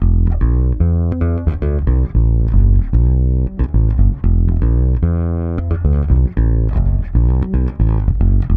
-AL AFRO F#.wav